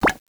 click_ui.wav